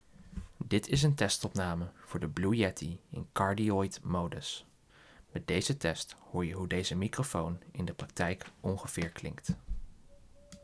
Blue Yeti - 0% gain - Cardioid - 30 cm afstand
Blue-Yeti-Cardioid-alleen-voor.m4a